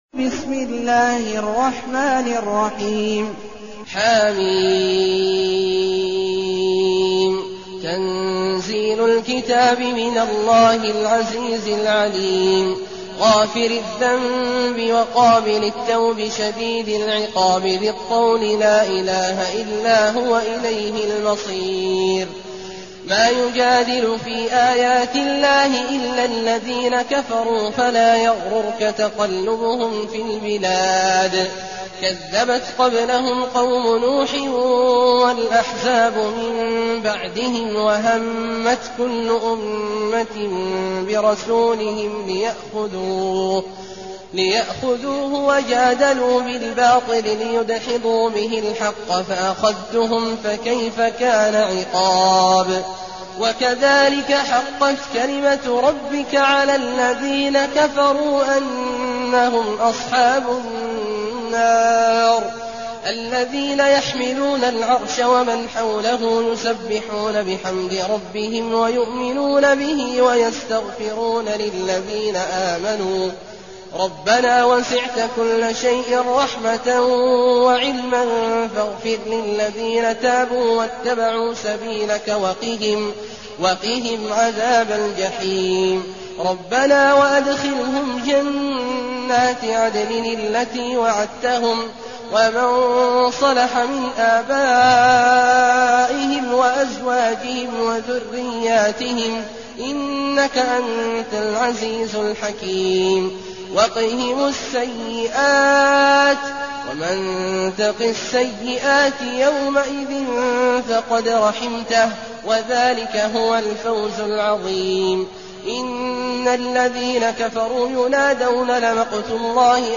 المكان: المسجد النبوي الشيخ: فضيلة الشيخ عبدالله الجهني فضيلة الشيخ عبدالله الجهني غافر